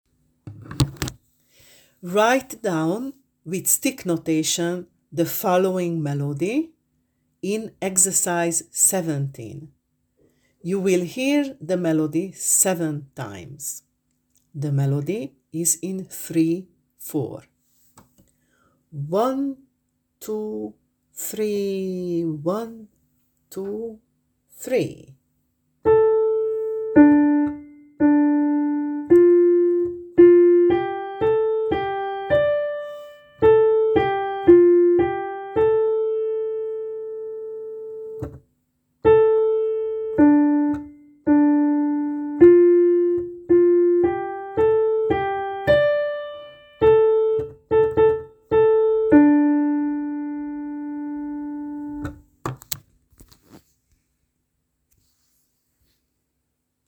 You will hear it 7 times: